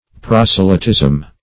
Proselytism \Pros"e*ly*tism\, n. [Cf. F. pros['e]lytisme.]